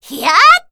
assassin_w_voc_crookingdeadfall02.ogg